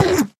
Sound / Minecraft / mob / endermen / hit2.ogg
should be correct audio levels.